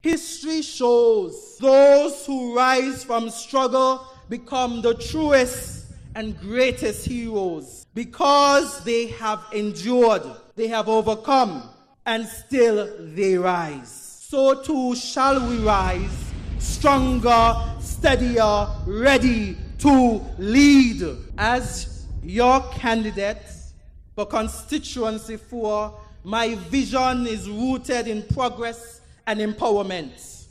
During its annual convention on June 1st, 2025, the People’s Action Movement (PAM) introduced two new candidates for constituencies #2 and #4.